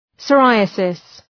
Προφορά
{sə’raıəsıs}